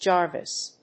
ジャービスジャーヴィス； ジャビス
発音記号
• / ˈdʒɑrvʌs(米国英語)
• / ˈdʒɑ:rvʌs(英国英語)